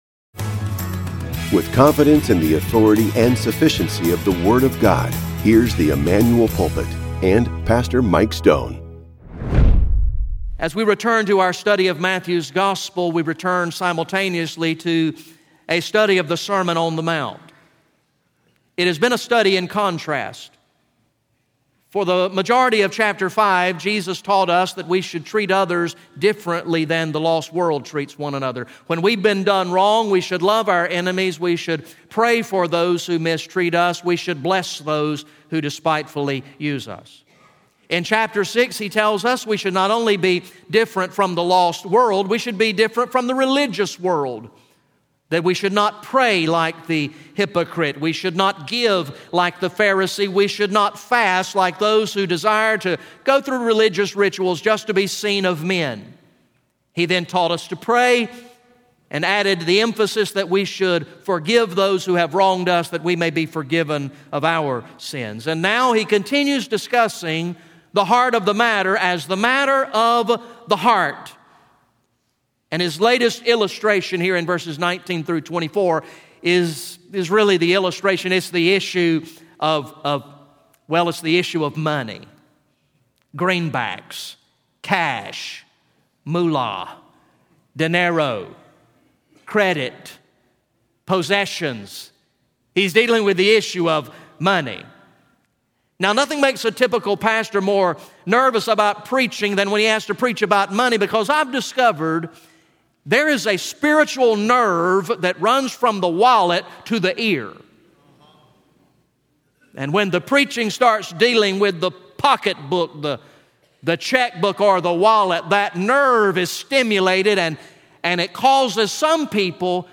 GA Message #26 from the sermon series titled “King of Kings